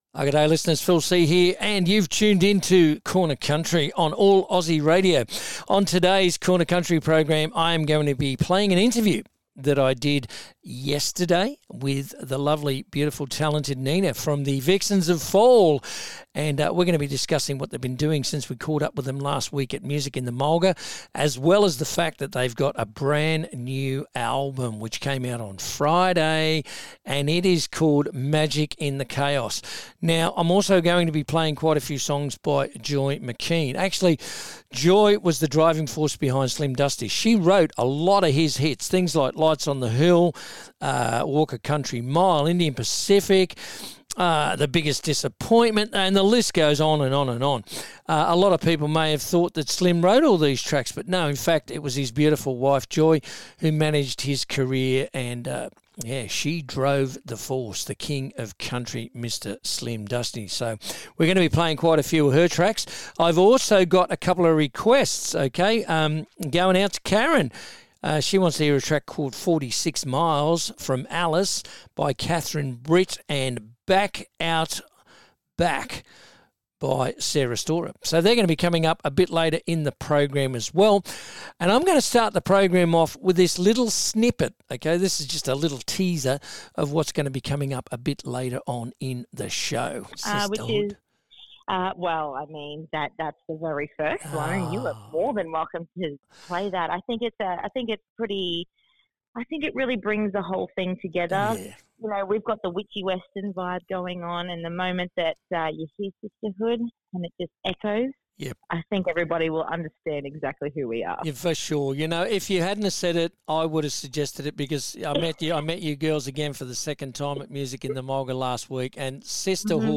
Includes interview